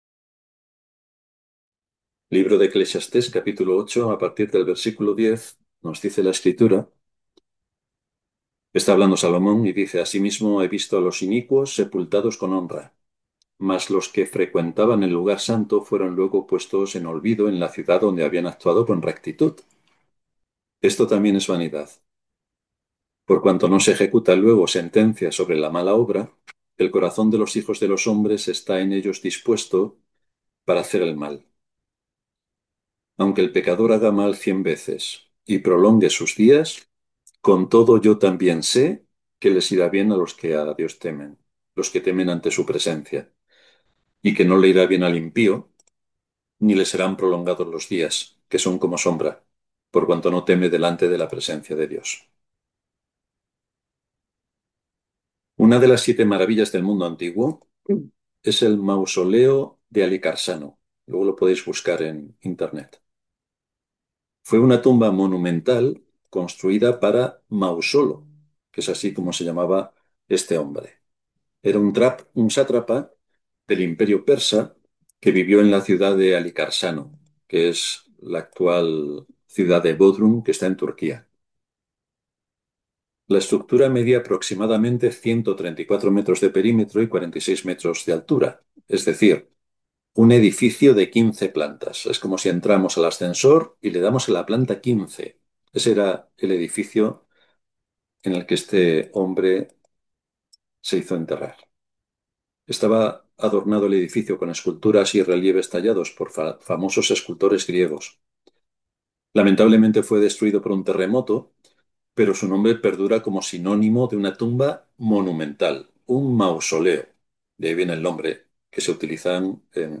SERIES DE PREDICACIONES